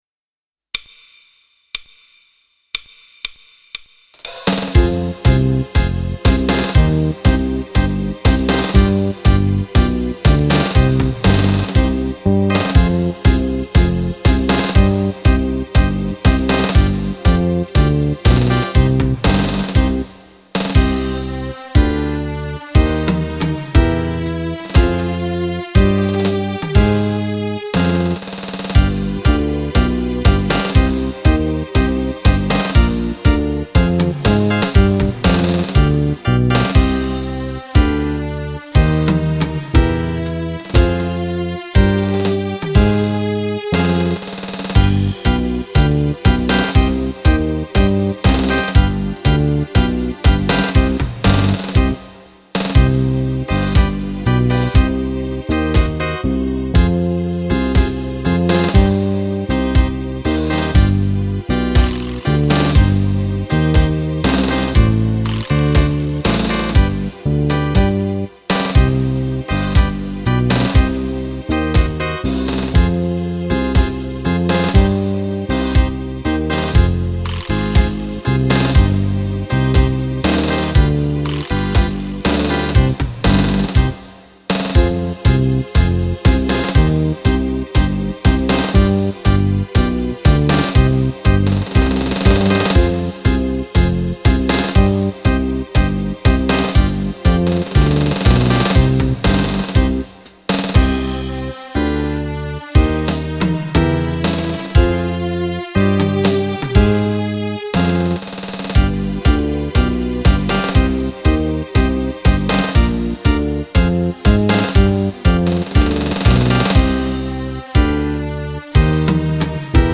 Tango
Fisarmonica